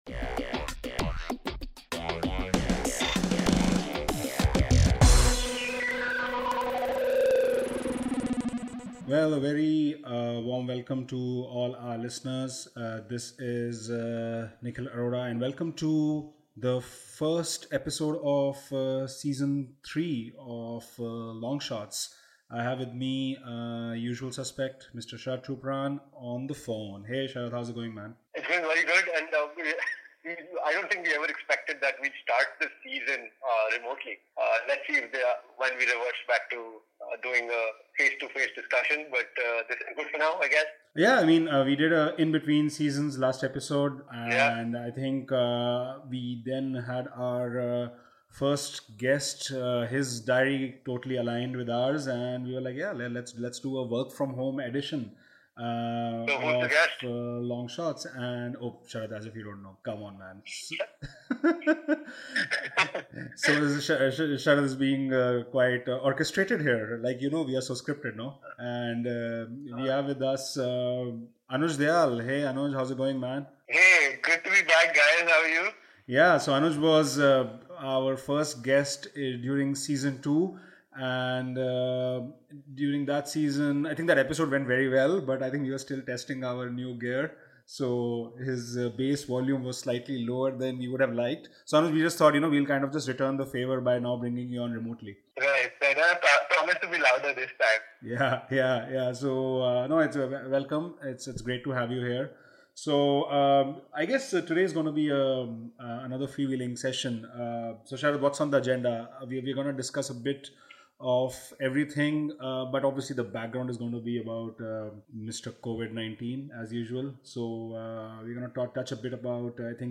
It's finally time to unveil the new season of LongShorts, and our first episode is a "work-from-home" recording!